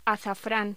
Locución: Azafrán